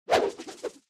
soceress_skill_inferno_01_intro.mp3